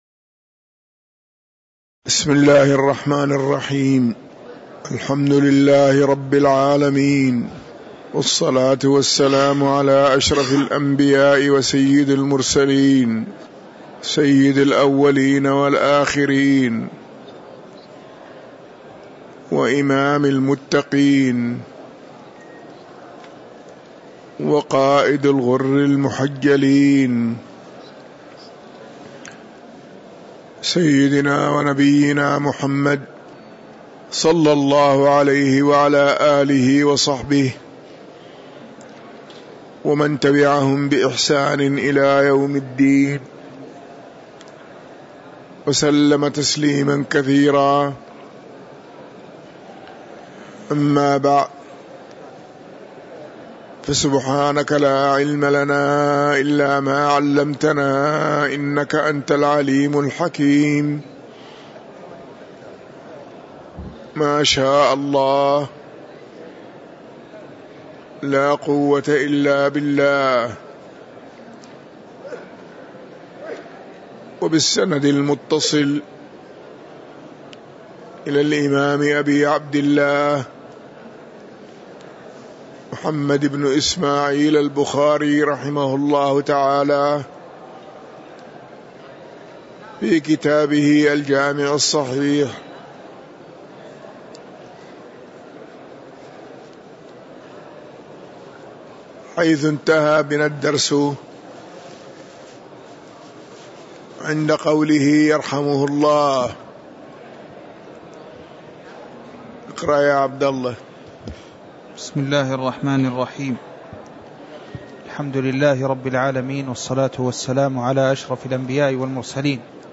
تاريخ النشر ٢٤ ربيع الثاني ١٤٤٣ هـ المكان: المسجد النبوي الشيخ